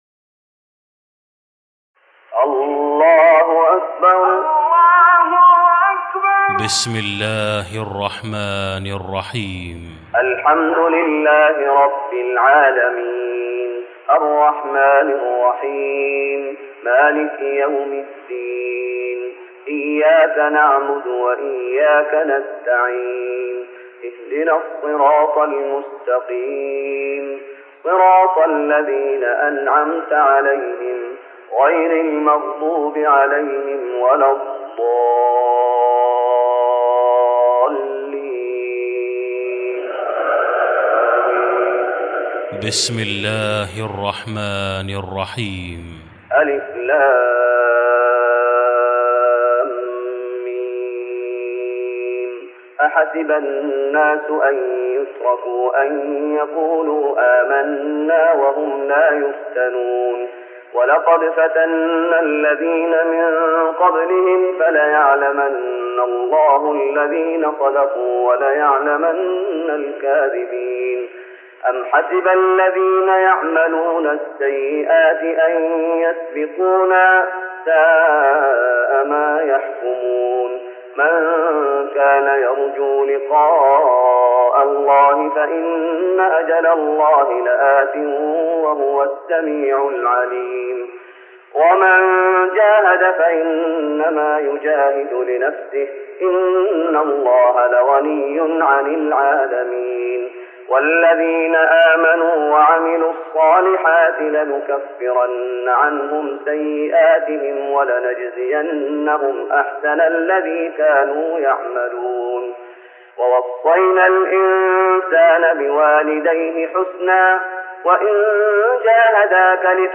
تراويح رمضان 1414هـ من سور العنكبوت (1-45) Taraweeh Ramadan 1414H from Surah Al-Ankaboot > تراويح الشيخ محمد أيوب بالنبوي 1414 🕌 > التراويح - تلاوات الحرمين